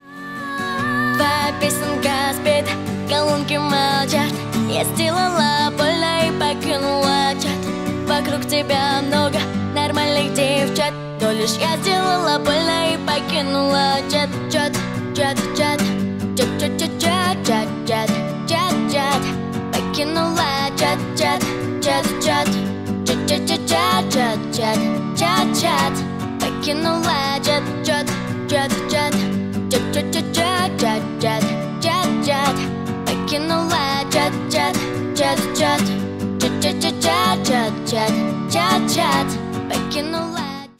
Поп Музыка
кавер # тихие